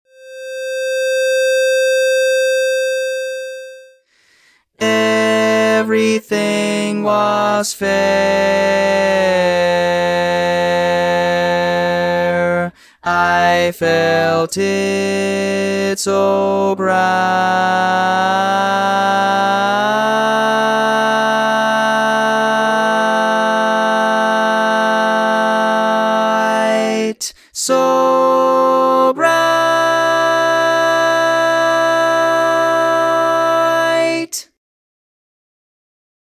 Key written in: C Major